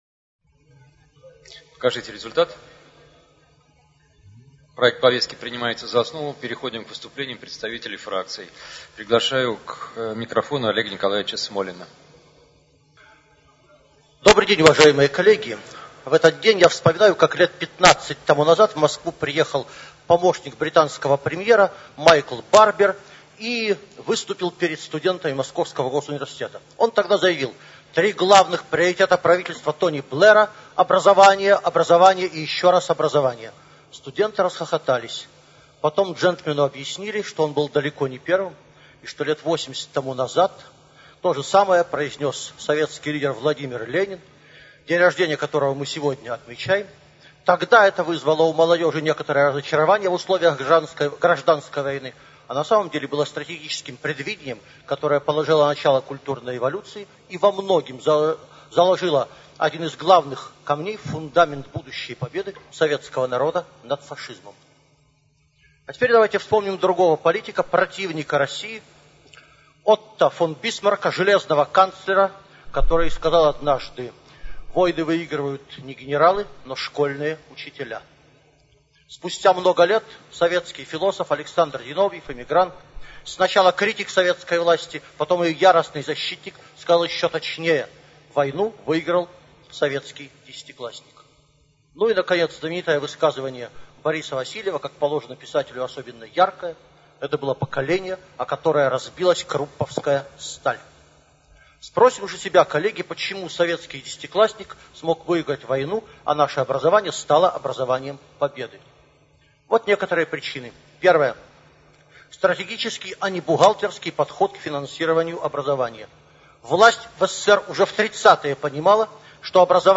Это выступление на пленарном заседании Государственной Думы Олег Николаевич озаглавил «Образование Победы и победа образования».